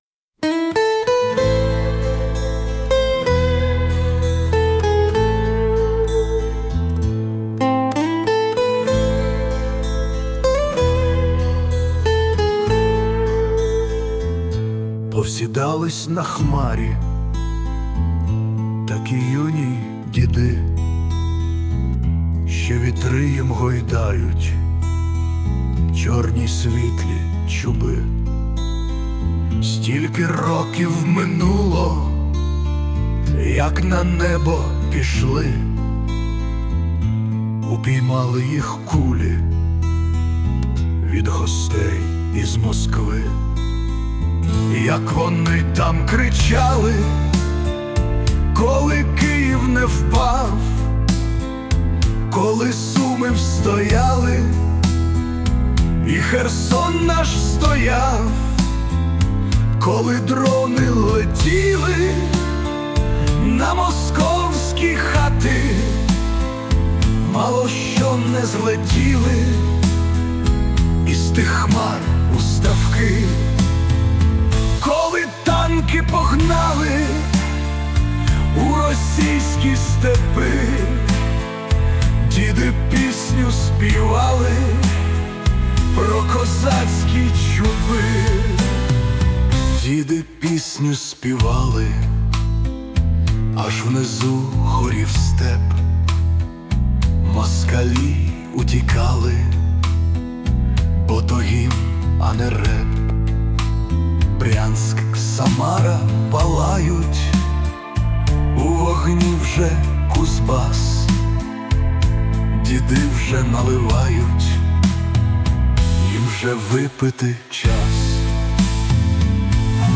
Присутня допомога SUNO